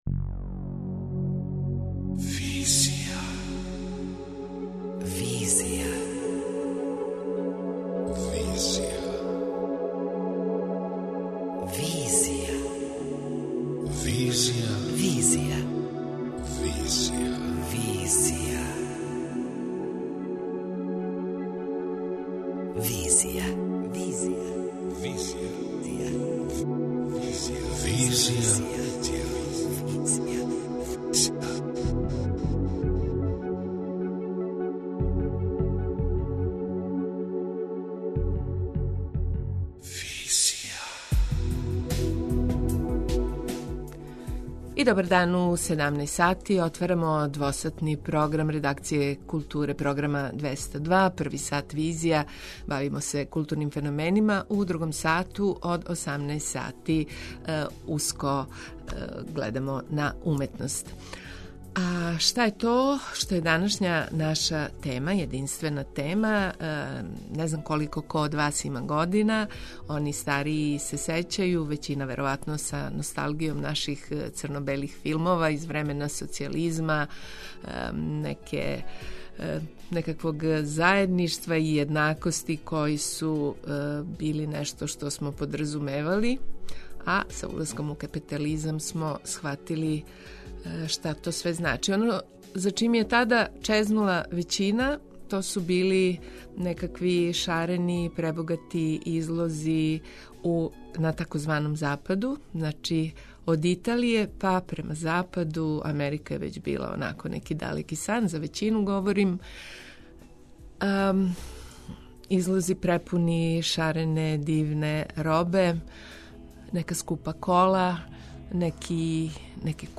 преузми : 27.43 MB Визија Autor: Београд 202 Социо-културолошки магазин, који прати савремене друштвене феномене.